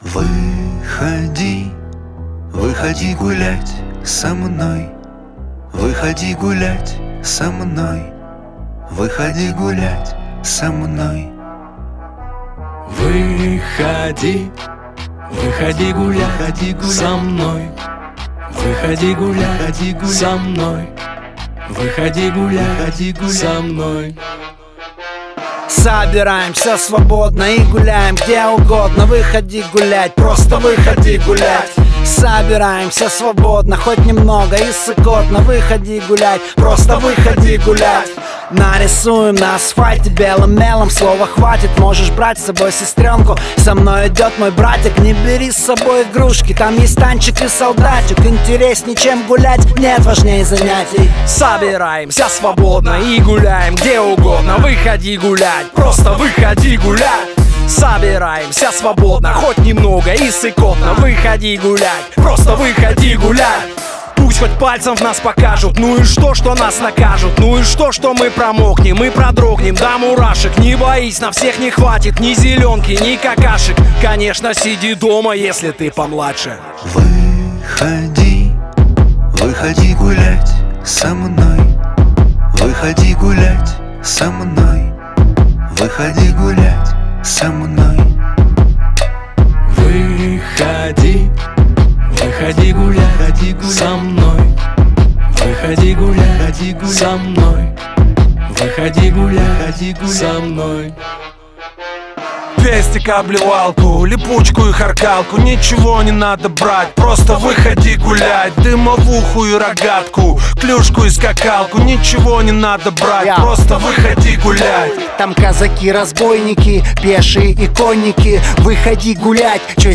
Жанр: Hip-Hop, Rap
Песни ироничные, тоскливые, трагичные.